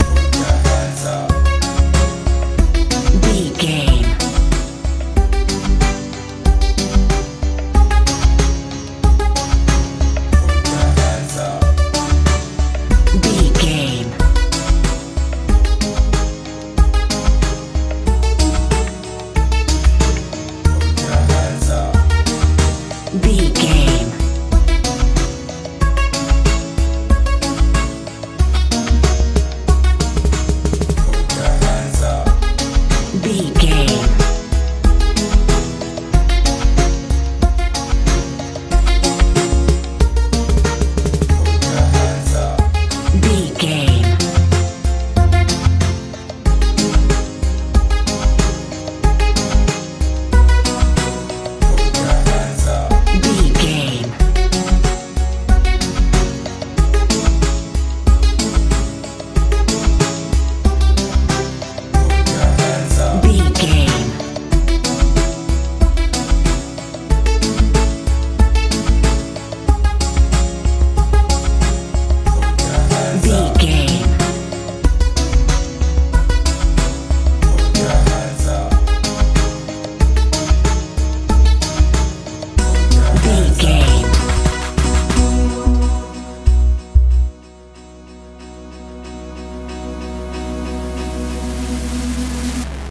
modern pop feel
Ionian/Major
C♯
mystical
strange
synthesiser
bass guitar
drums
80s
90s